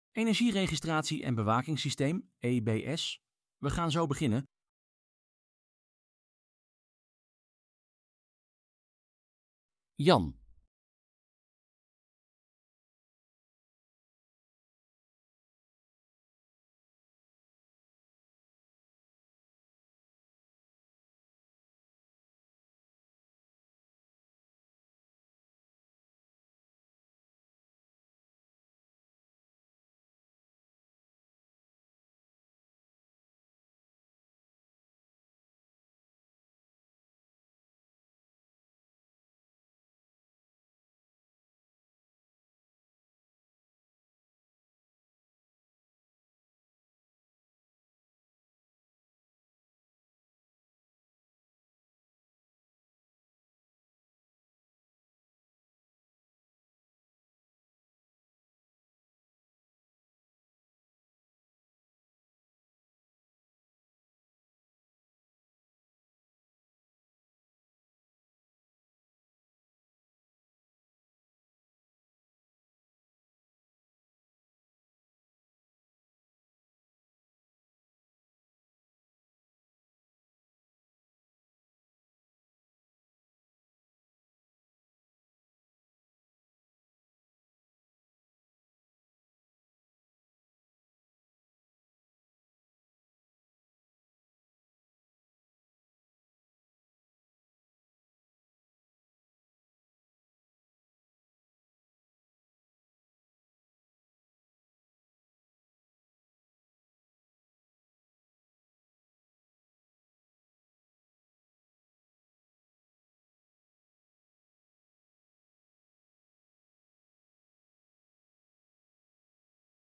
Er zijn 3 sprekers: Webinar host